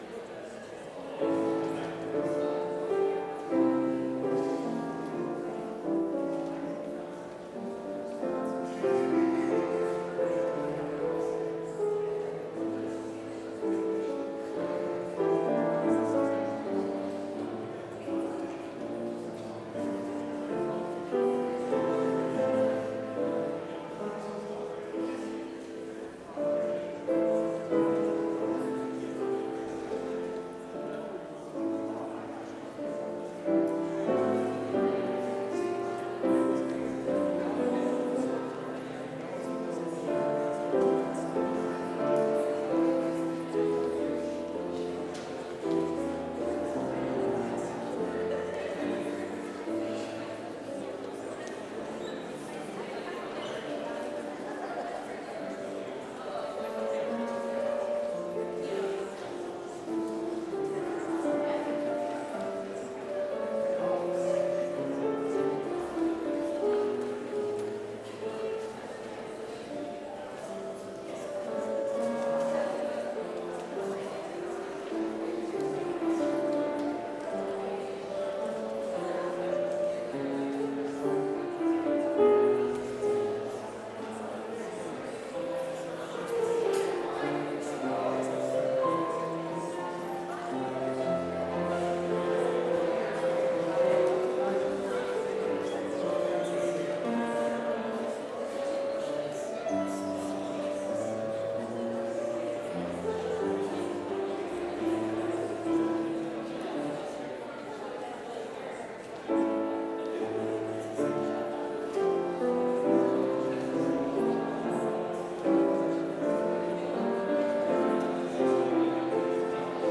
Complete service audio for Chapel - Thursday, September 4, 2025
Watch Listen Complete Service Audio file: Complete Service Sermon Only Audio file: Sermon Only Order of Service Psalm 63 - O Lord, I Will Sing of Your Constant Love - C. Walker Soloist: O Lord, I will sing… / Cong.: O Lord, I will sing…